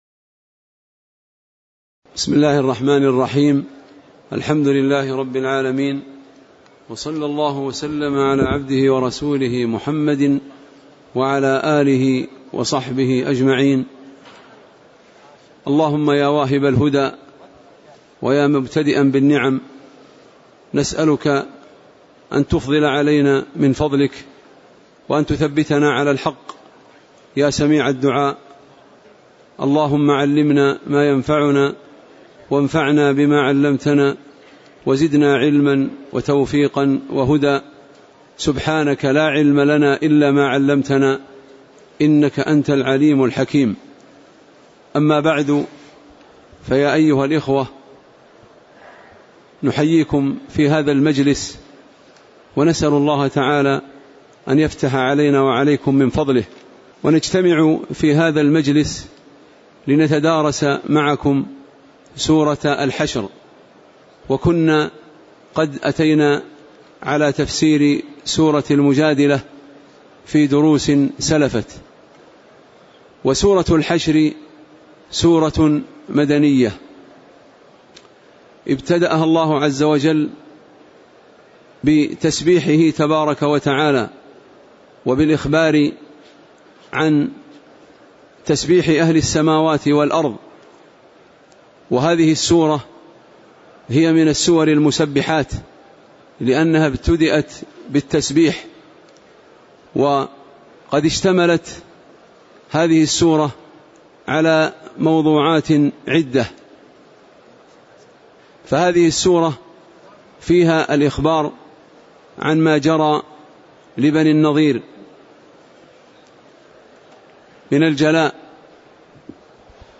تاريخ النشر ٤ رجب ١٤٣٨ هـ المكان: المسجد النبوي الشيخ